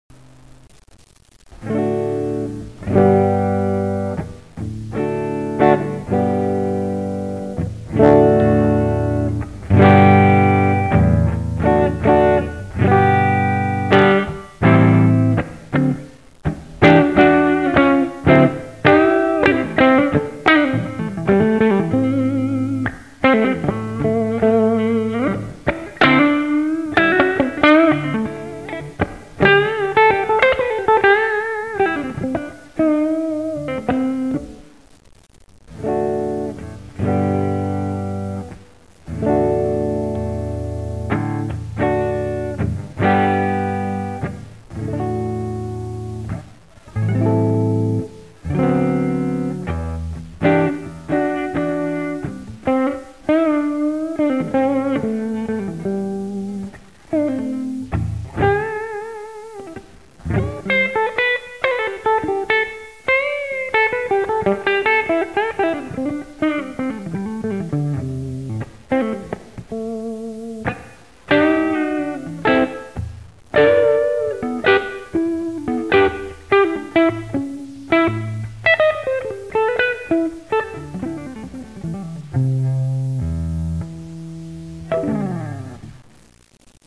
I did these clips quickly so I would not pass out - please excuse the roughness of the sound and playing...
Note 3: In the heat my S470 developed a buzz, so there is some buzz in the HB clips - it's my guitar/playing not the VC
Setup1: Ibanez S470 (w/Gibson Classic '57/'57+ pickups) -> VAMP (for small combo)->computer sound card
Clip 1: Clean Neck HB: VC set to Dr:30%, Tr:50%, B:50%, Vol:70% :I played using the neck HB pickup w/out the VC on for a D-G-D chord set then added it - so you hear the change when activated, then I played the same blues like stuff in all of these clips
vc_4_clean_HB.mp3